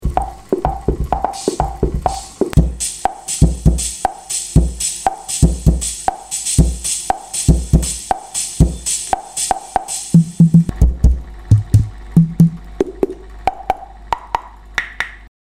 It had a spinning disc with contact points to make drum sounds like bass drums, snares, brushes, cymbals, and tambourines.
It also had preset rhythms for popular styles like waltzes and sambas.
The Wurlitzer Sideman